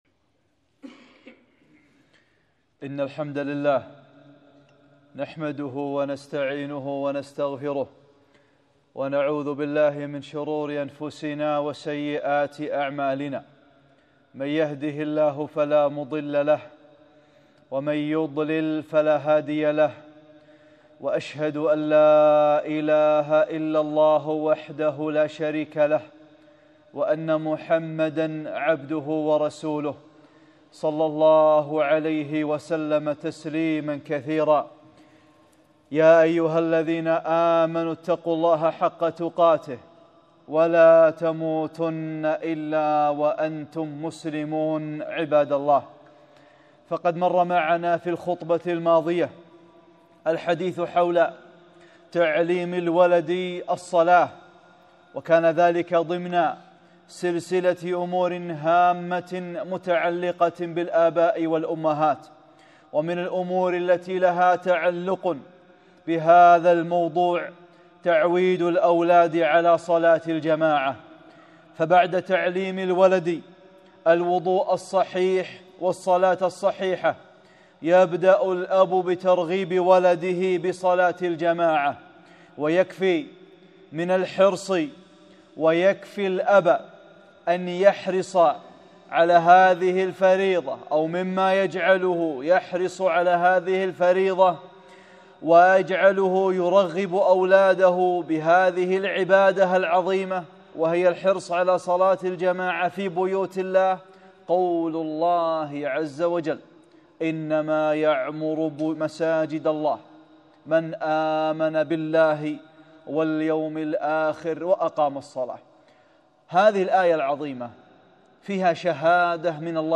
(29) خطبة - صلاة الجماعة | أمور هامة متعلقة بالآباء والأمهات